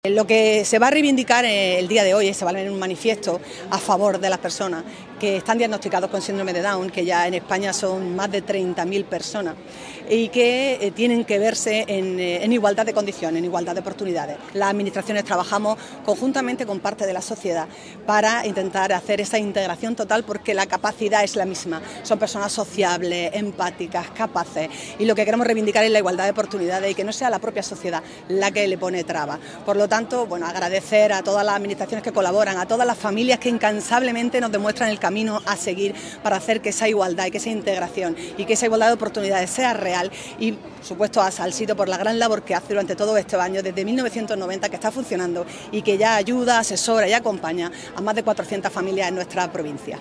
ALCALDESA-LECTURA-MANIFIESTO-SINDROME-DOWN-ASALSIDO.mp3